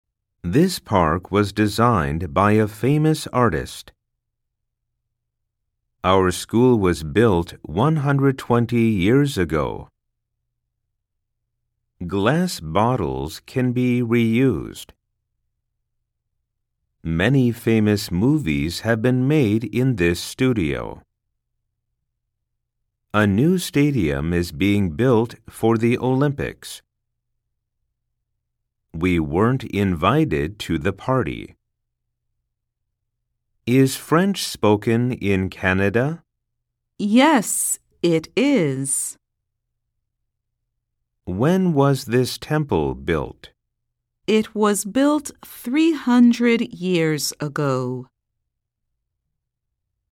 Target例文 slow speed　サンプル音声（mp3）